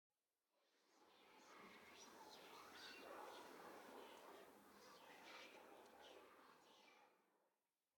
Minecraft Version Minecraft Version snapshot Latest Release | Latest Snapshot snapshot / assets / minecraft / sounds / ambient / nether / soulsand_valley / whisper3.ogg Compare With Compare With Latest Release | Latest Snapshot
whisper3.ogg